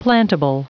Prononciation du mot plantable en anglais (fichier audio)
Prononciation du mot : plantable